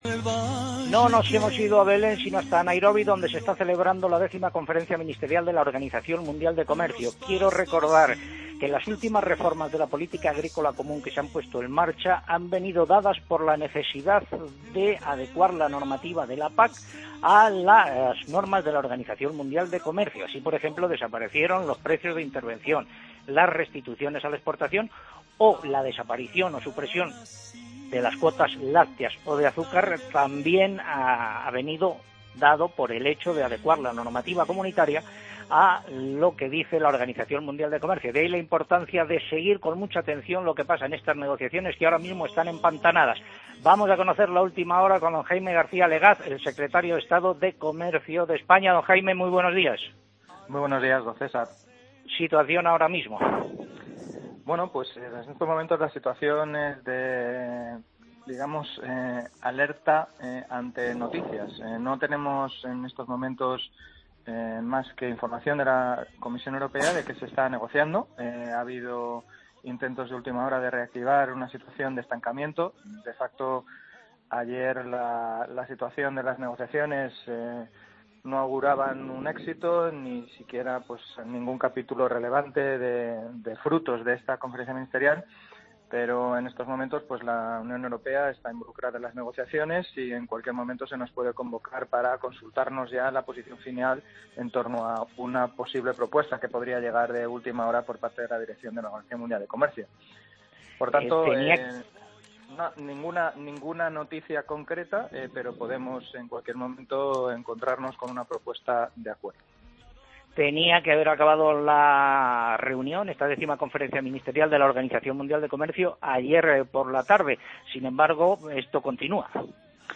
Jaime García Legaz, Secretario de Estado de Comercio y Turismo, da la última hora sobre las negociaciones de la OMC en Nairobi